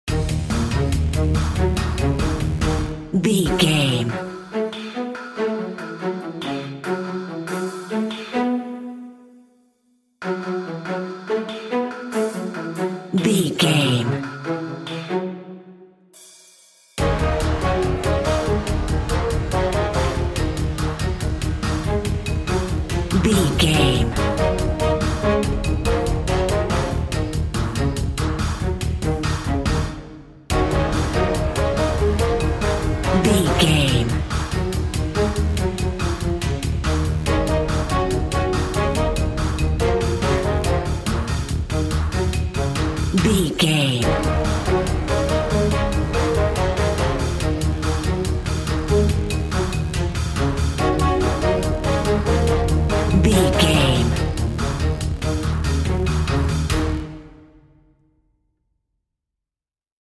Aeolian/Minor
E♭
epic
driving
intense
medium tempo
bass guitar
drum machine
orchestra
horns
percussion
synthesiser